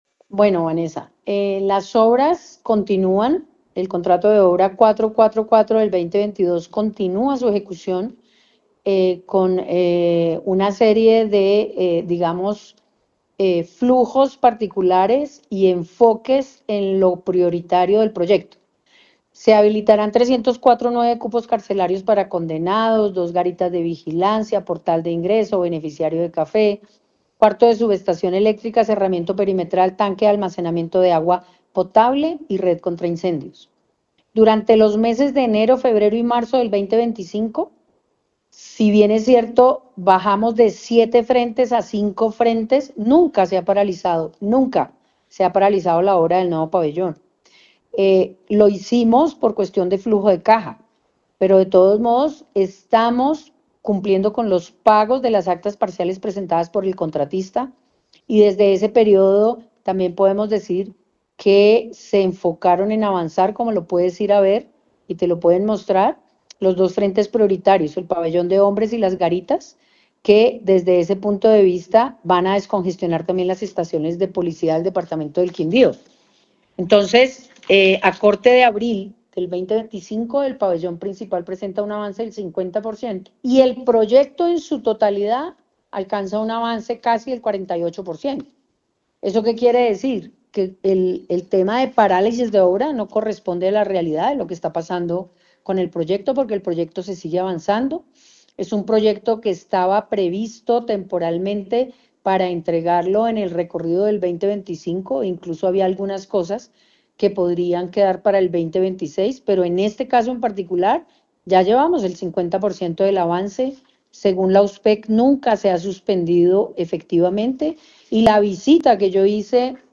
En diálogo con Caracol Radio Armenia, la ministra de Justicia Ángela María Buitrago, aclaró que contrario a lo que indicaron autoridades en el departamento, el proyecto del nuevo pabellón se viene ejecutando con normalidad.